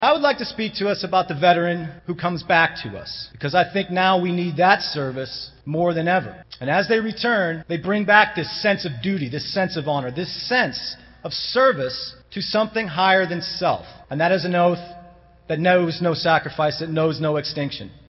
Manhattan pauses for Veterans Day activities